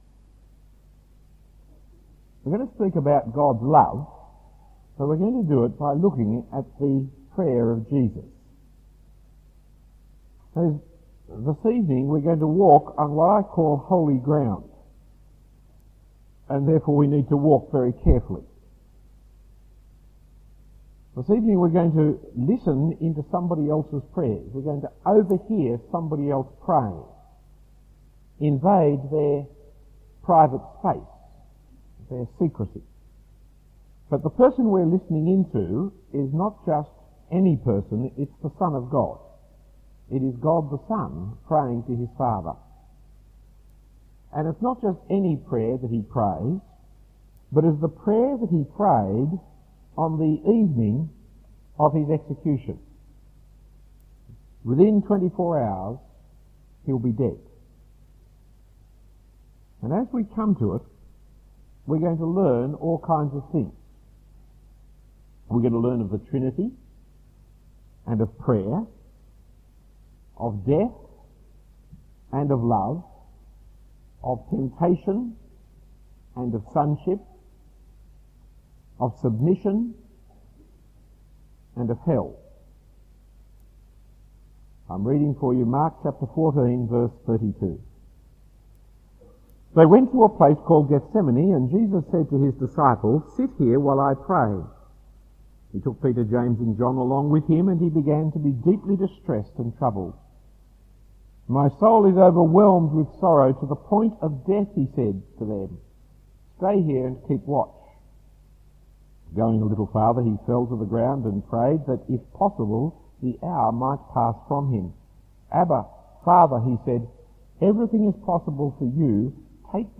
Talk 4 of 7 in the series MYC 1998 God’s Strange Work.